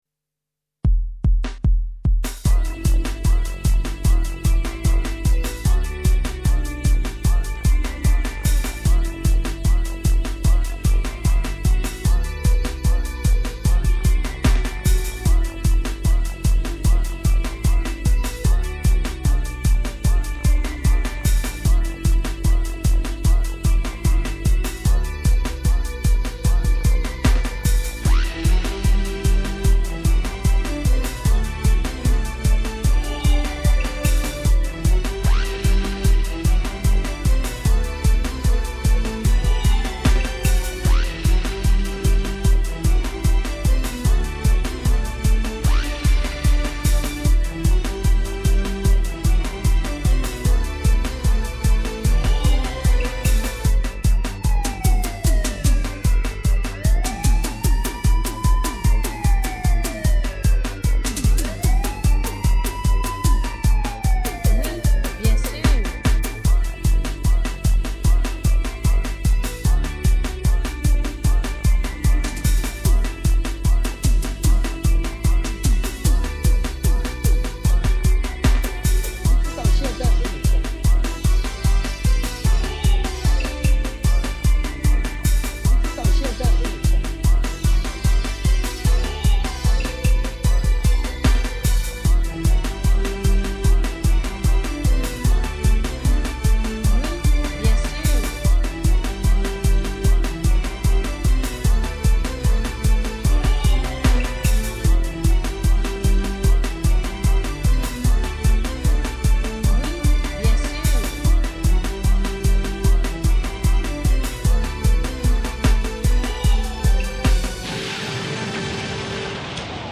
Real Techno-Pop.